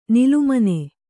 ♪ nilumane